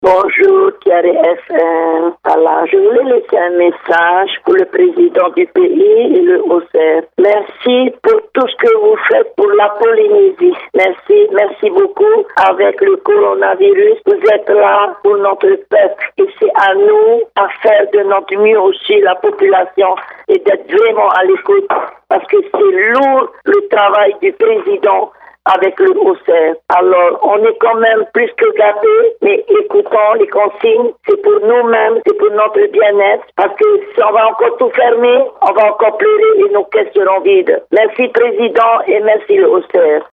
Répondeur de 6:30, le 04/08/2020